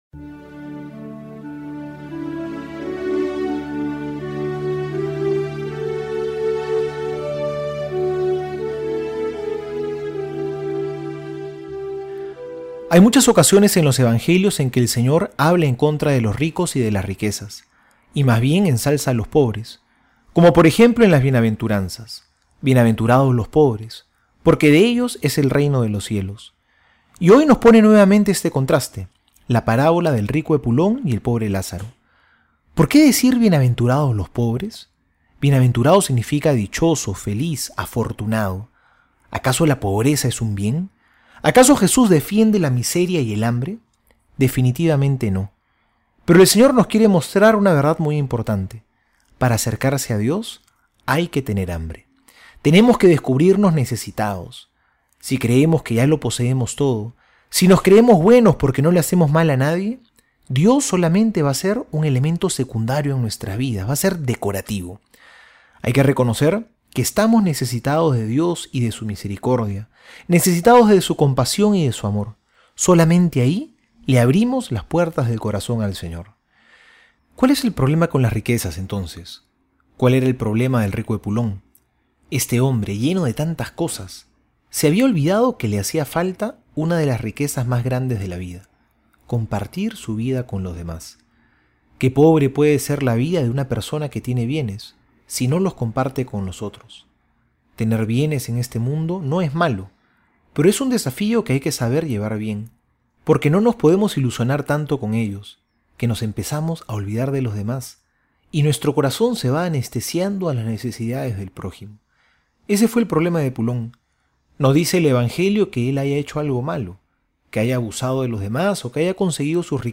Homilía para hoy: